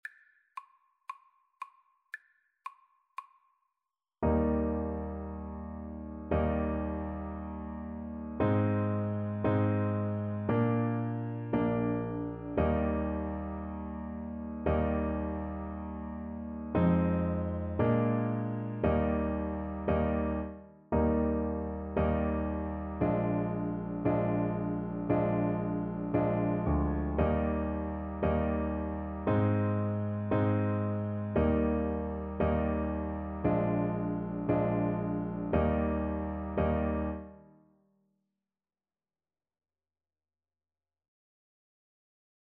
Eb major (Sounding Pitch) (View more Eb major Music for Violin )
4/4 (View more 4/4 Music)
Traditional (View more Traditional Violin Music)